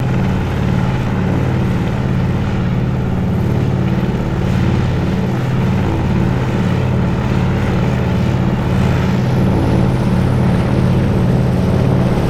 Caribou Two Engine Plane Steady